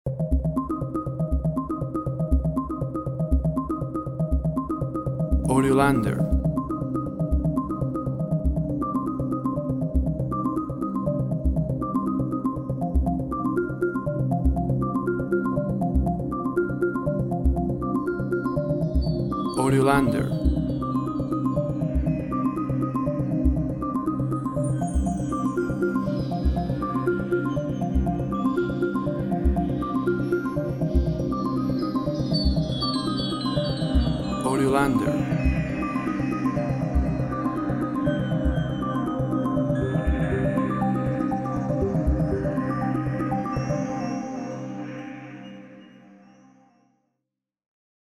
Sequencer sound, with pad and other sound FX.